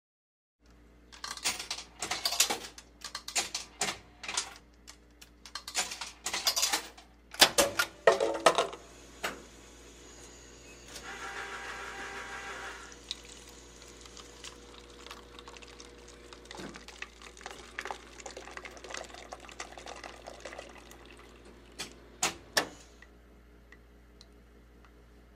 Tiếng Máy pha Cafe, coffee… trong quầy pha chế
Thể loại: Tiếng động
Description: Âm thanh rít của hơi nước, tiếng xay hạt cà phê lách tách và tiếng nhỏ giọt của espresso trong một quán cà phê thực thụ.
tieng-may-pha-cafe-coffee-trong-quay-pha-che-www_tiengdong_com.mp3